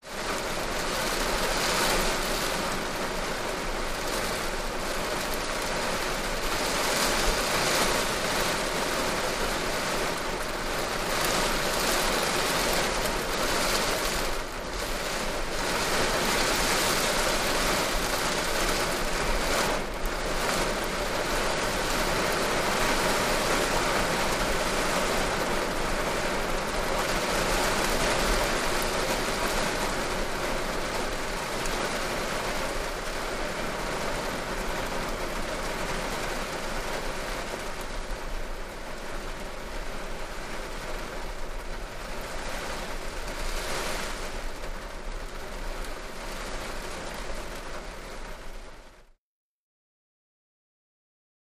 Rain On Car; Heavy Waves, Interior Perspective